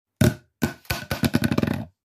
cardboardpipe.mp3